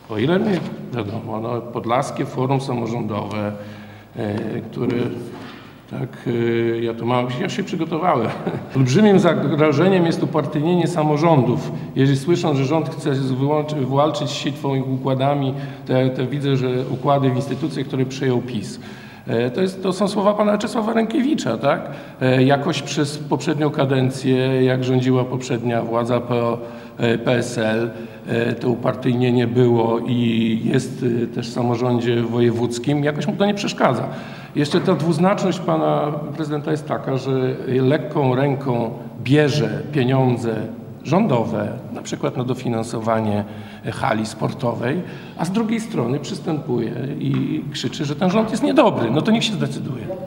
Takie stanowisko Grzegorz Mackiewicz przedstawił w środę (09.08), podczas konferencji prasowej poświęconej organizacji Święta Wojska Polskiego.